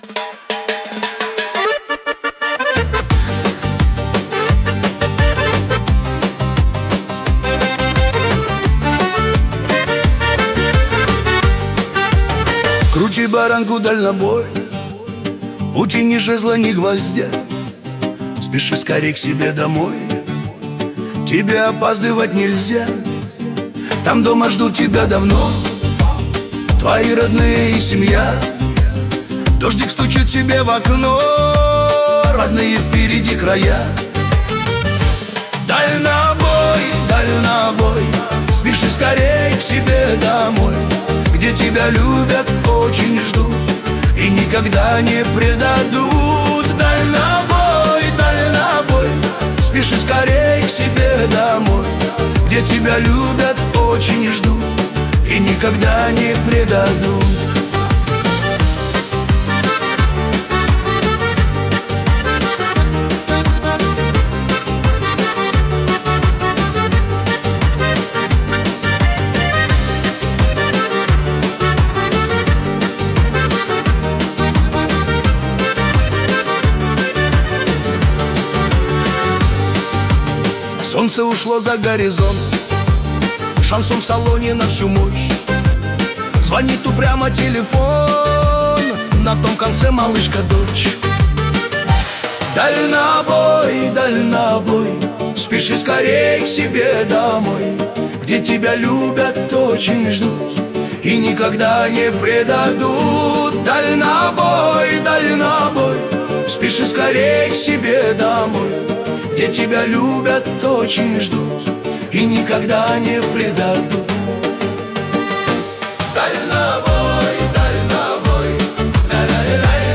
14 декабрь 2025 Русская AI музыка 93 прослушиваний